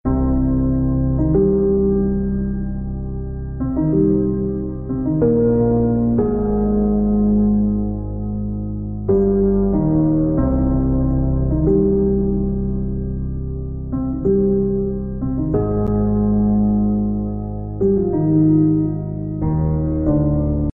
黑暗钢琴
描述：黑暗的东西
标签： 93 bpm Cinematic Loops Piano Loops 3.49 MB wav Key : Unknown
声道立体声